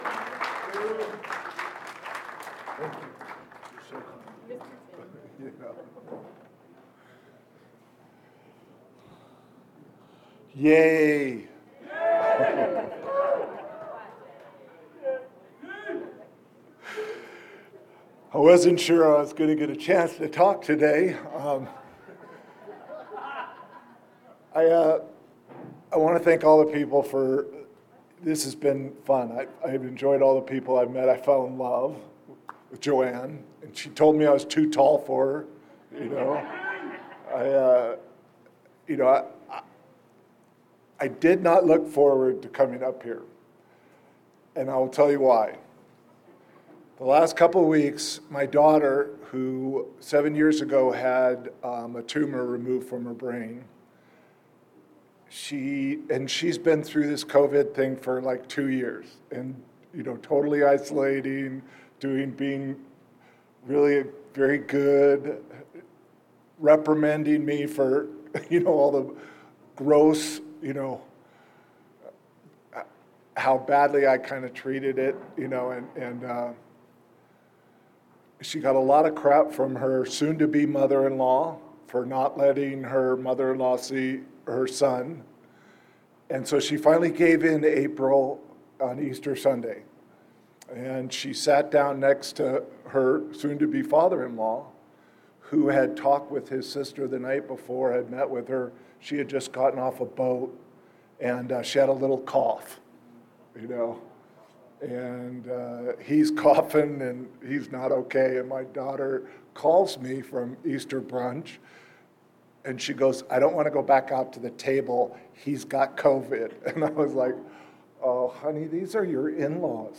32nd Indian Wells Valley AA Roundup